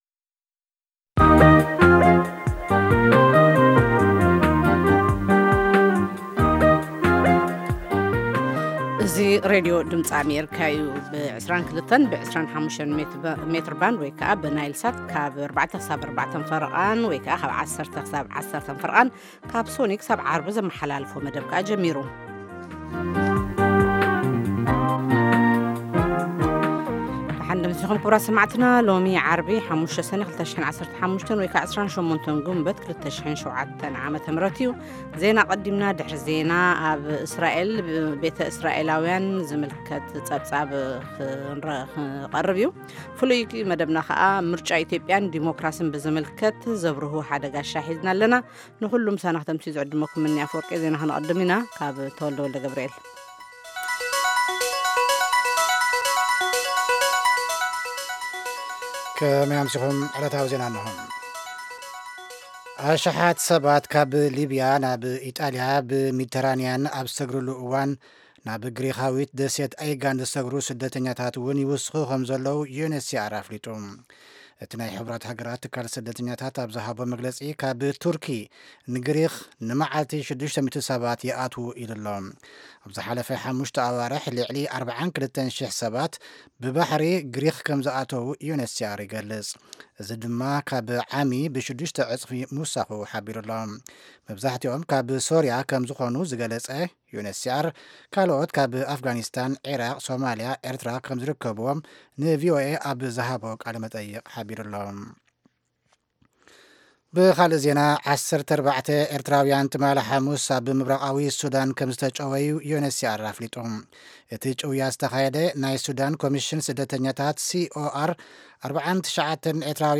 ፈነወ ትግርኛ ብናይ`ዚ መዓልቲ ዓበይቲ ዜና ይጅምር ። ካብ ኤርትራን ኢትዮጵያን ዝረኽቦም ቃለ-መጠይቓትን ሰሙናዊ መደባትን ድማ የስዕብ ። ሰሙናዊ መደባት ዓርቢ፡ ቂሔ-ጽልሚ / ፍሉይ መደብ/ ሕቶን መልስን